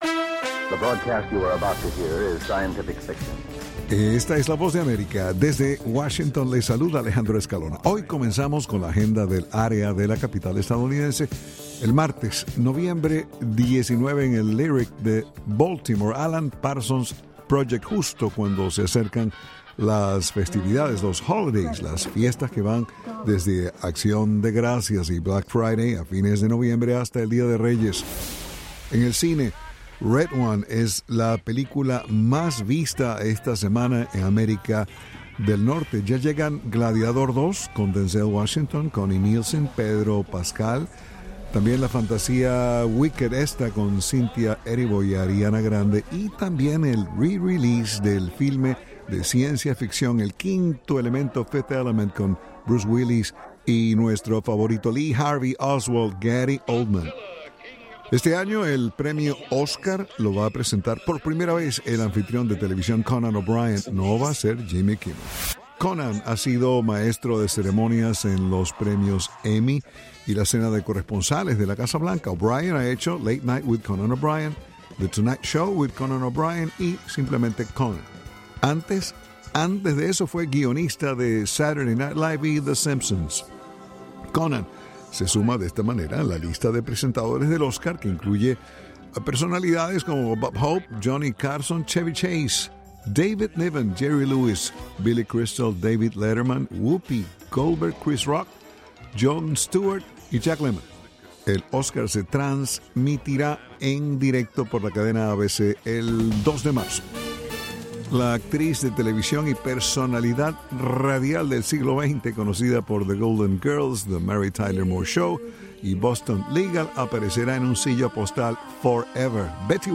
noticias del espectáculo